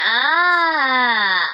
SW_Ahhhhh
Tags: Star Wars japanese dub